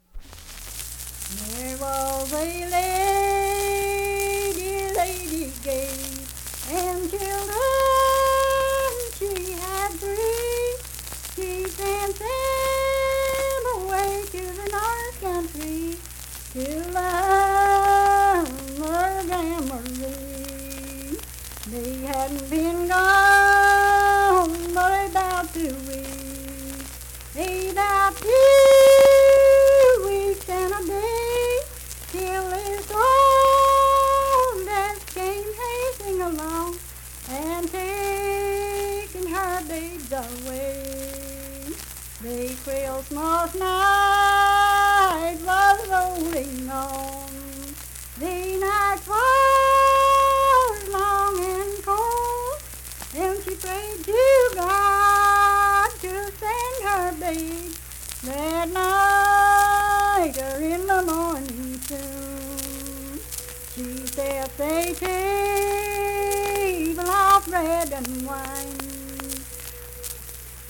Unaccompanied vocal music performance
Verse-refrain 3(4).
Voice (sung)